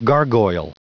Prononciation du mot gargoyle en anglais (fichier audio)
Prononciation du mot : gargoyle